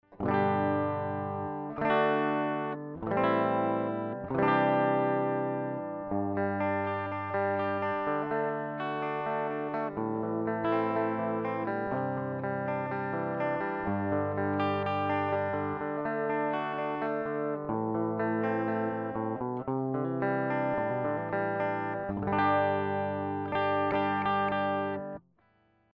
It uses an Elixir strings and connected to my preamp through Hi-Z No matter how I turn the knobs and the switches, I could not get a bright clean electric guitar sound like I usually hear. It seems that it lacks the high end frequency.
I attached a sample of my guitar sound View attachment Clean Electric Guitar.mp3